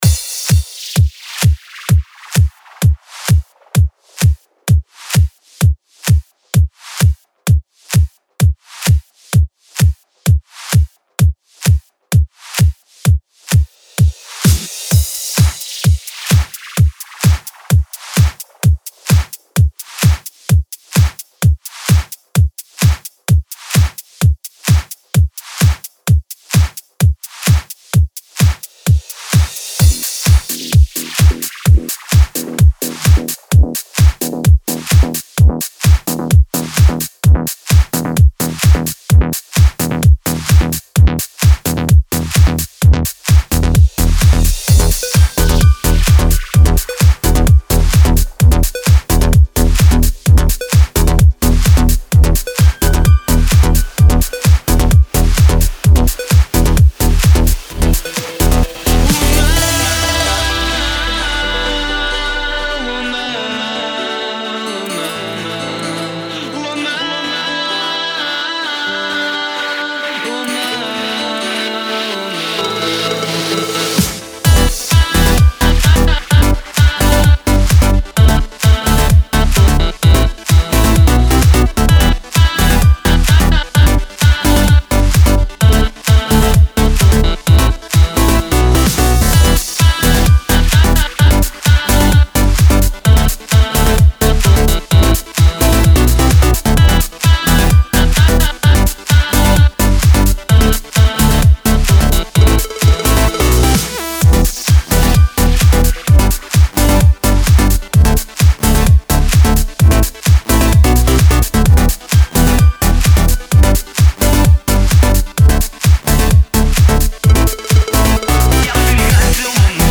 Главная » Клубная музыка